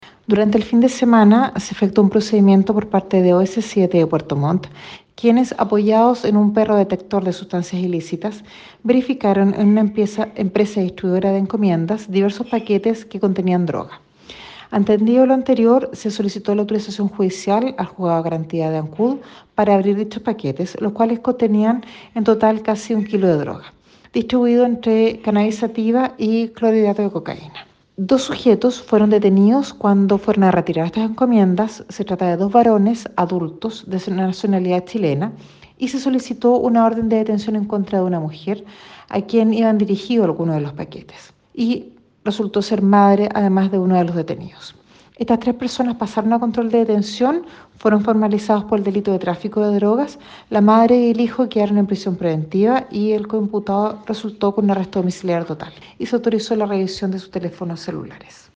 Pormenores de los operativos llevados a efecto de manera encubierta y que culminó exitosamente con la detención de los involucrados, entregó la fiscal de Ancud, Pilar Werner, quien indicó que dos de los aprehendidos quedaron en prisión preventiva.
17-FISCAL-OPERATIVO-DROGA-ANCUD.mp3